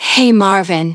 synthetic-wakewords
ovos-tts-plugin-deepponies_Naoto Shirogane_en.wav